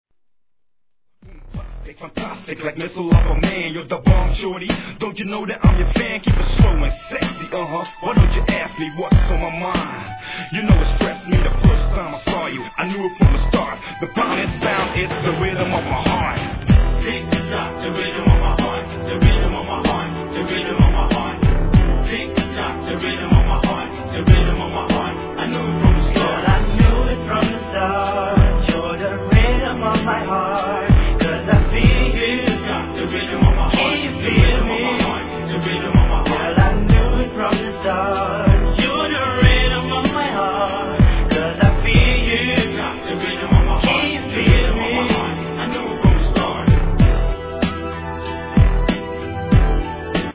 HIP HOP/R&B
重なり合うシンセが哀愁漂わせるトラックに、フックのコーラスが一層雰囲気を煽ります!!